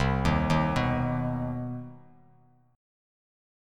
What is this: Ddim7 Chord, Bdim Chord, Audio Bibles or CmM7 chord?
CmM7 chord